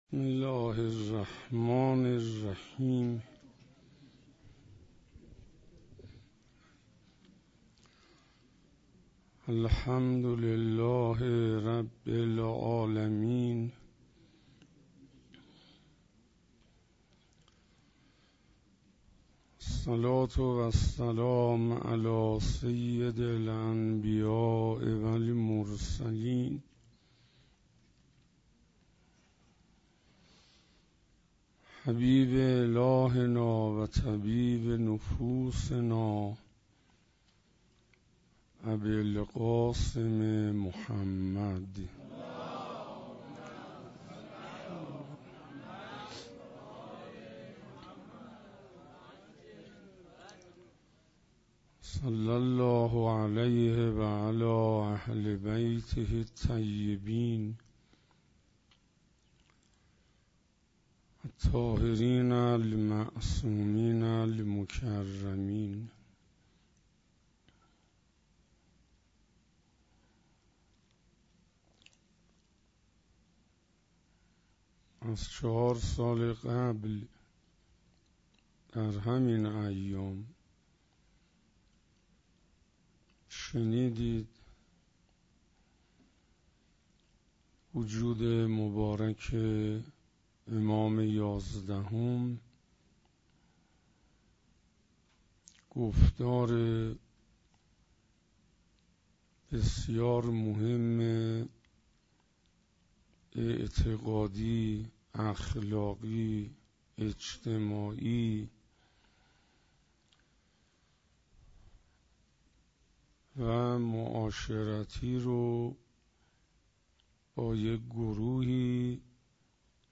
حسینیه آیت الله ابن الرضا - شب ششم - تقوای الهی